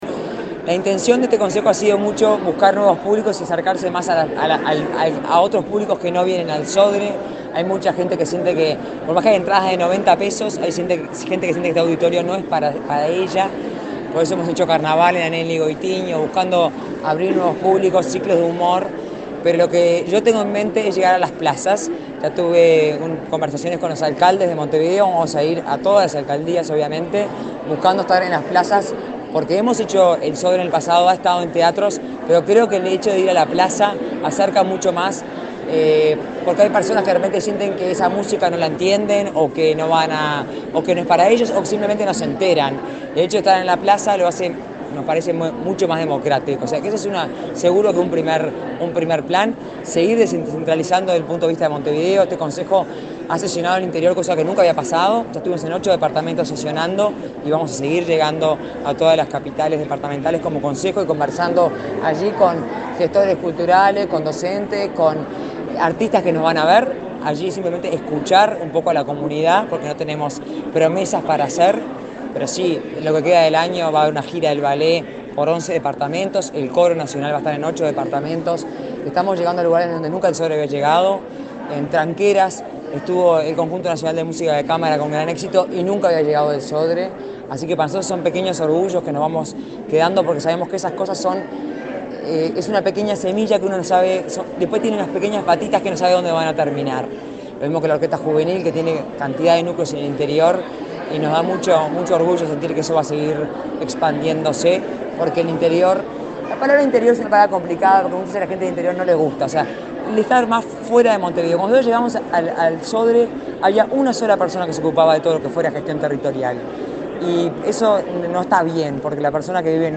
Declaraciones de la presidenta del Sodre a la prensa